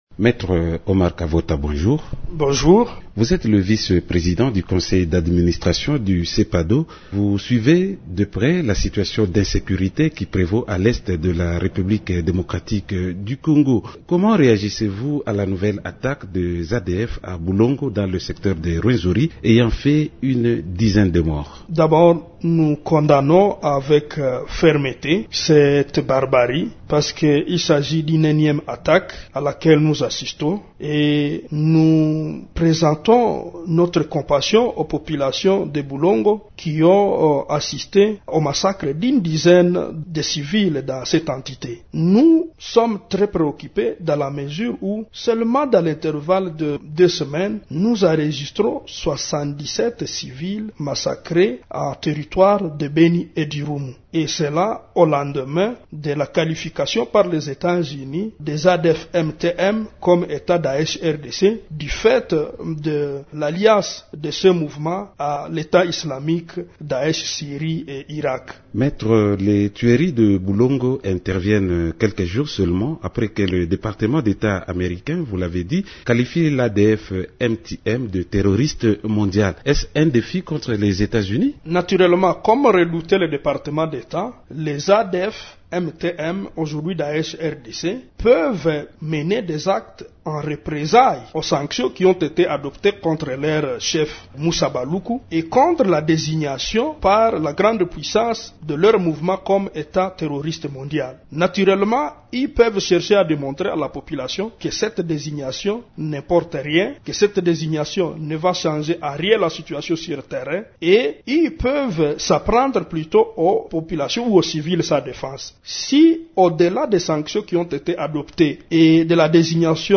L'invité du jour, Émissions / retrait, M23, Ndeye Khady Lo, mandat, Monusco, résolution